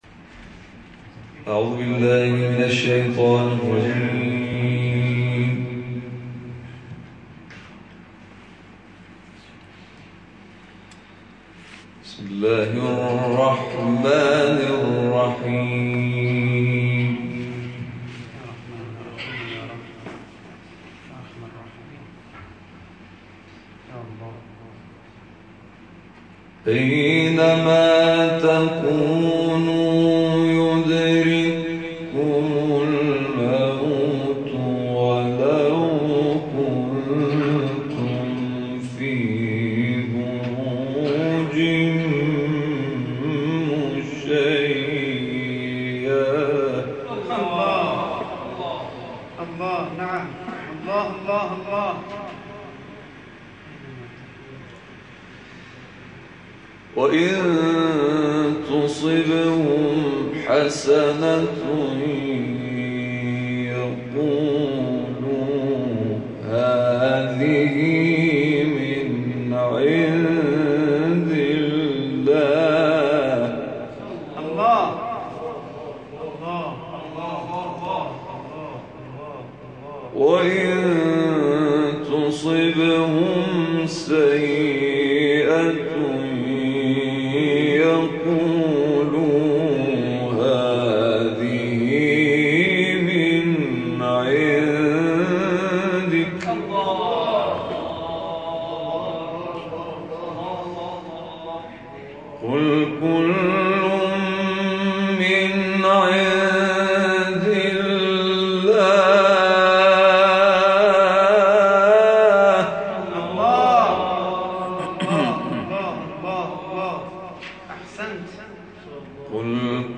تلاوتی
این تلاوت در شهر کاشان اجرا شده است و مدت زمان آن 27 دقیقه است.